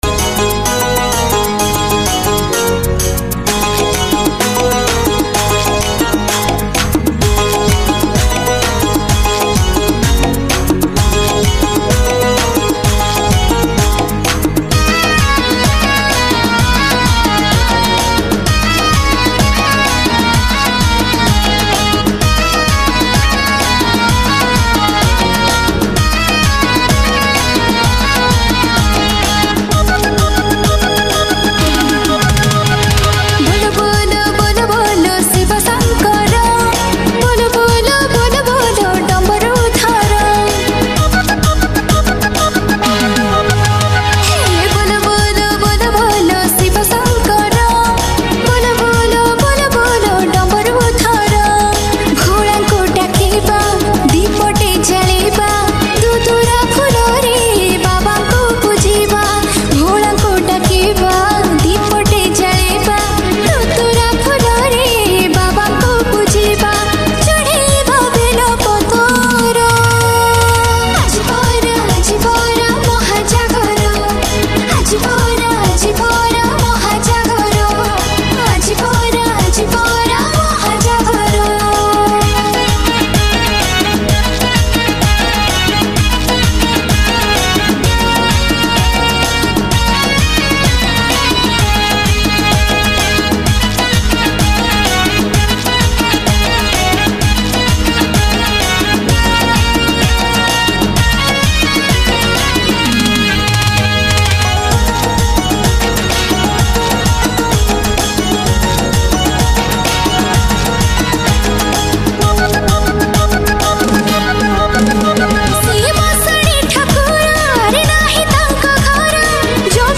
Jagara Special Odia Bhajan Song Upto 2021 Songs Download